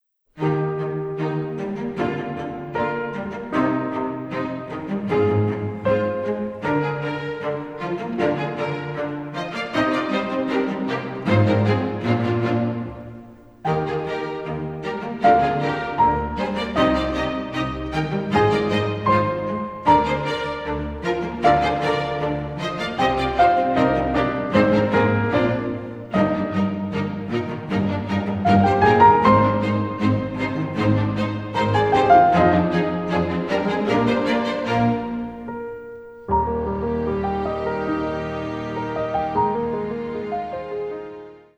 is more intimate and nuanced
a delicate and melancholic writing